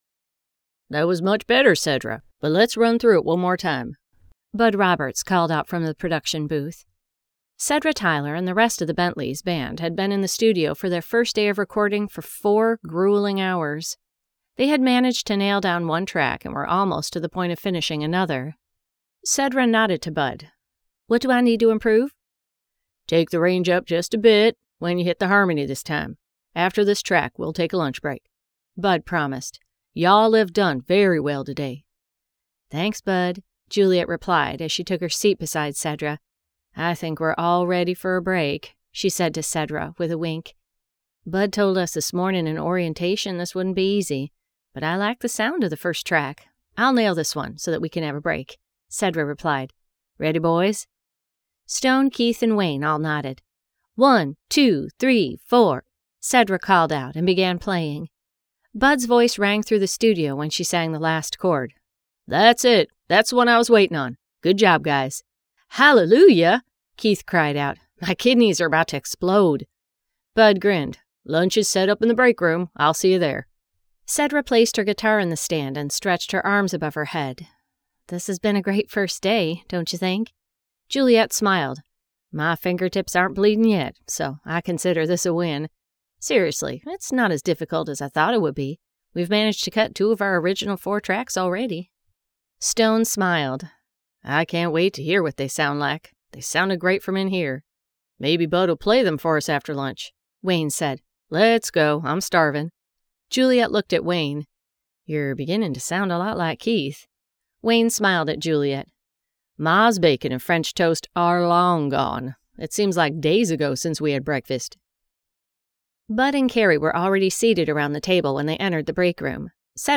Midnight in Nashville by Ali Spooner Songwriters Book 2 [Audiobook]